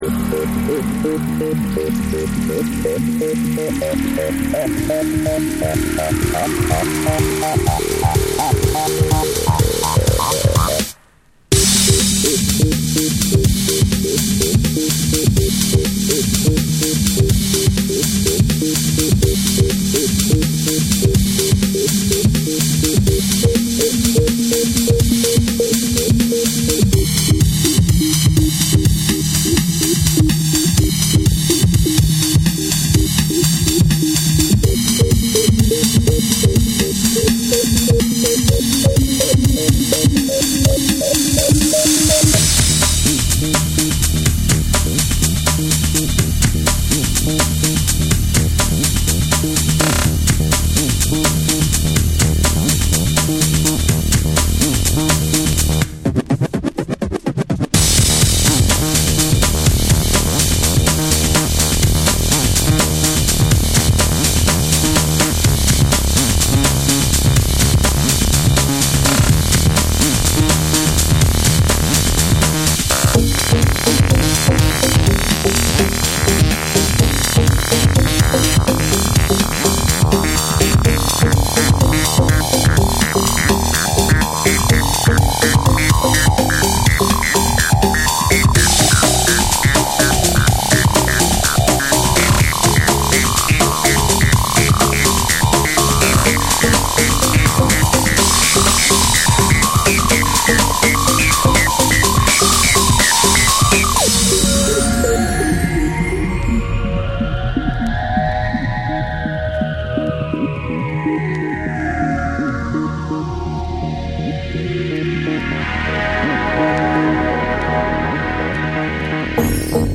アシッディーなシンセが渦巻く